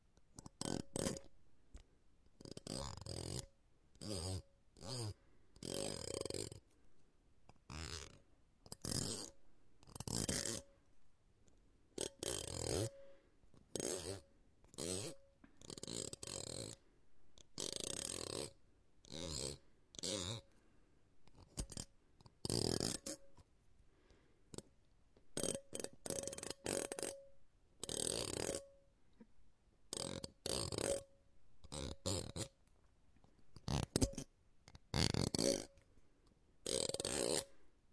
描述：用一支笔打另一支笔。